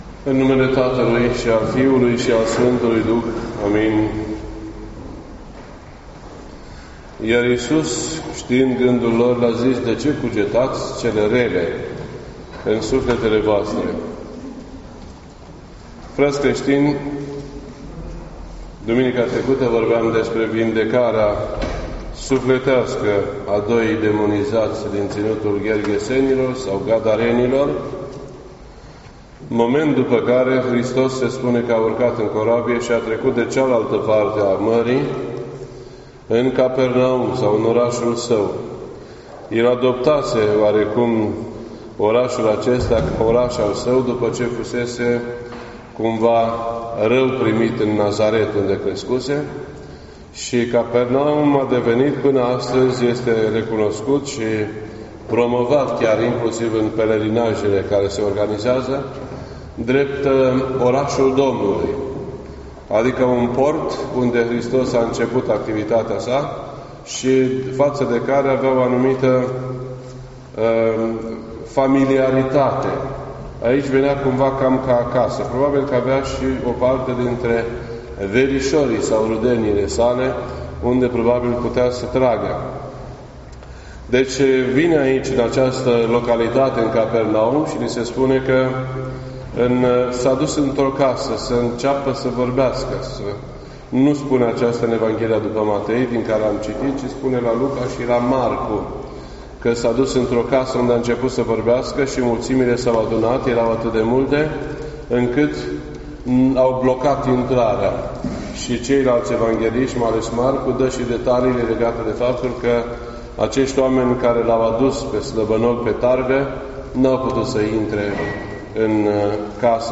This entry was posted on Sunday, July 8th, 2018 at 1:31 PM and is filed under Predici ortodoxe in format audio.